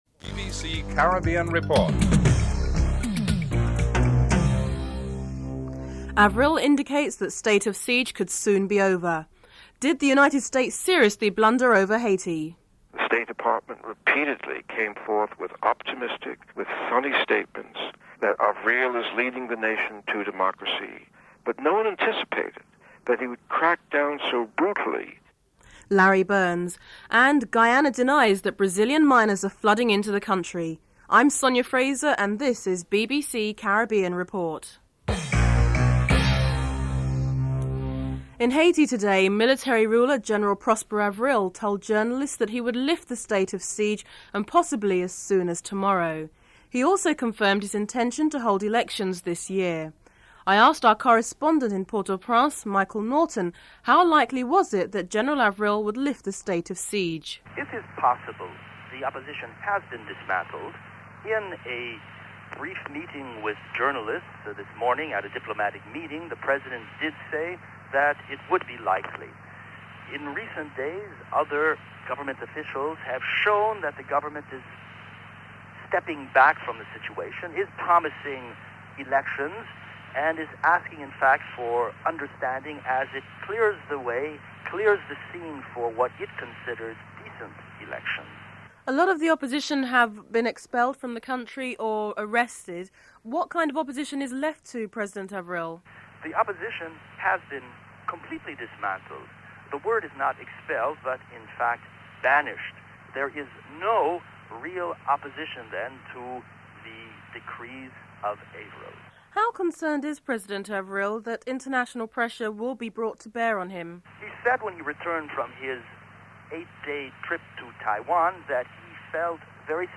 Following the Financial Market news, a Brazilian correspondent reports that a large number of gold prospectors are crossing the border into Guyana.
1. Headlines (00:00-00:37)
3. Financial News.